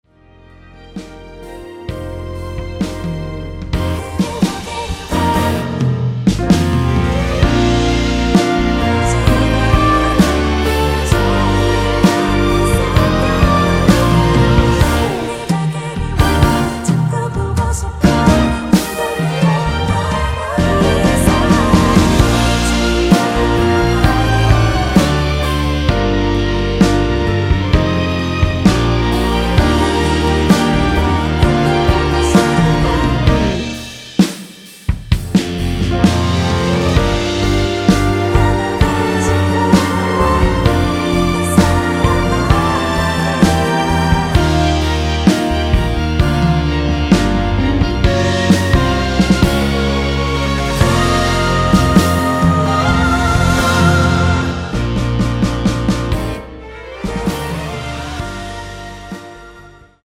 원키 코러스 포함된 MR입니다.(미리듣기 확인)
Bb
앞부분30초, 뒷부분30초씩 편집해서 올려 드리고 있습니다.